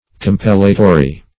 Compellatory \Com*pel"la*to*ry\, a. Serving to compel; compulsory.